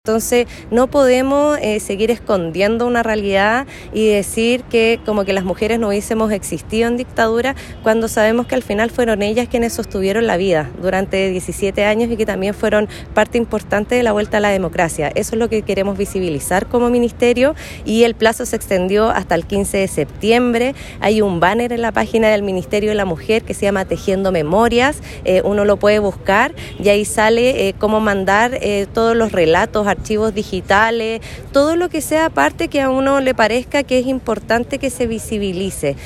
La Seremi indicó que se busca visibilizar el lugar que tuvieron las mujeres, por lo que esta iniciativa busca recopilar parte importante de la historia que no se ha contado.